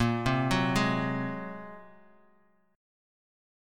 Bb7sus2#5 Chord